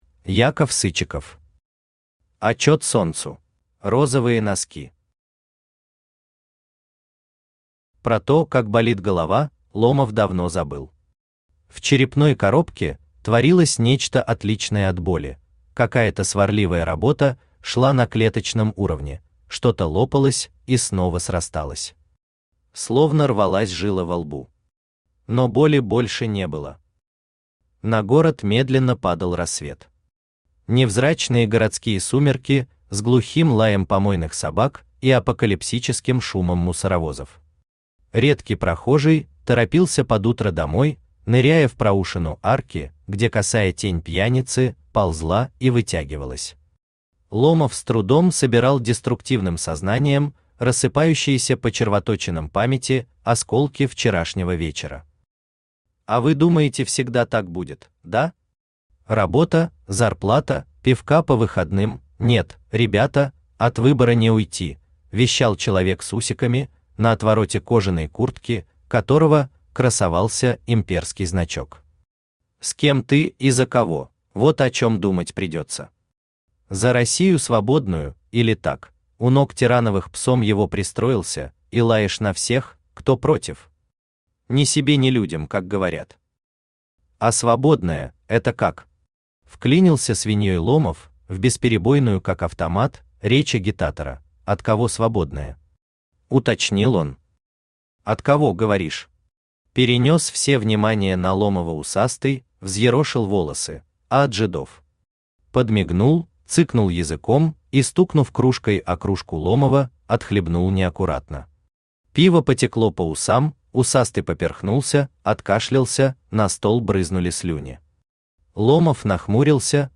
Аудиокнига Отчет Солнцу | Библиотека аудиокниг
Aудиокнига Отчет Солнцу Автор Яков Сычиков Читает аудиокнигу Авточтец ЛитРес.